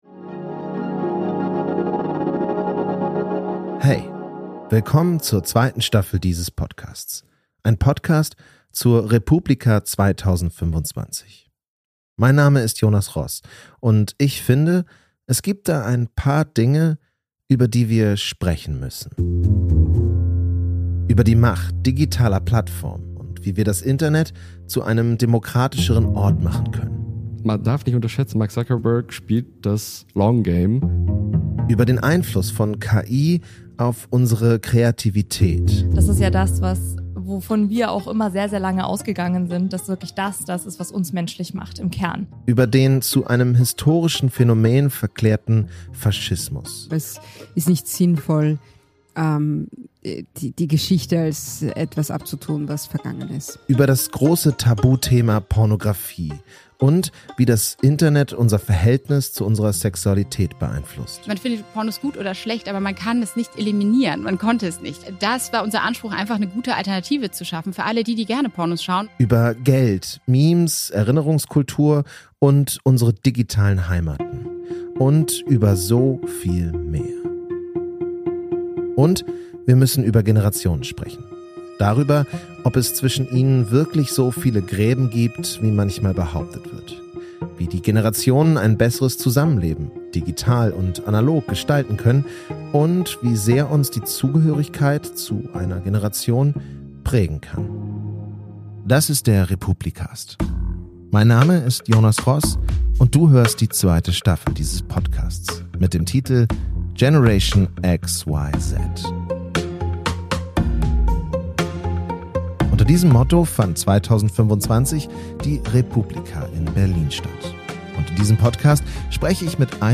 Trailer: Der re:publicast ist zurück mit Staffel 2 – Generation XYZ ~ re:publicast Podcast